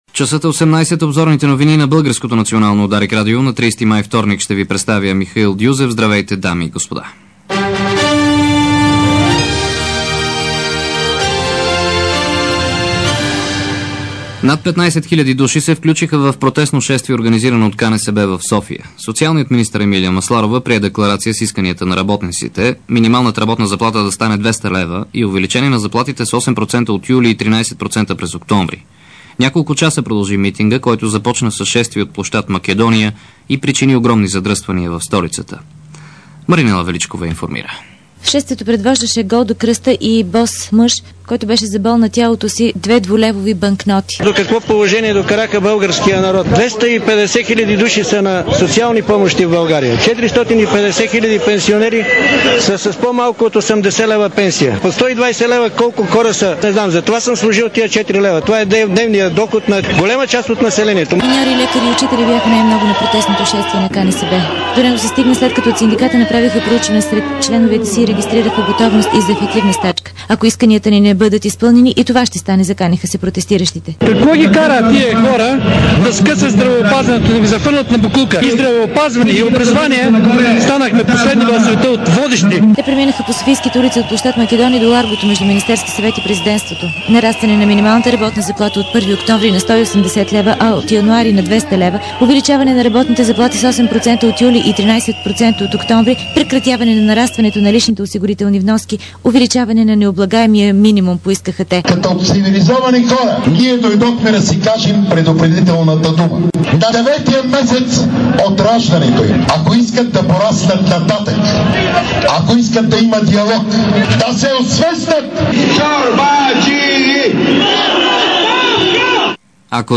DarikNews audio: Обзорна информационна емисия 26.04.2006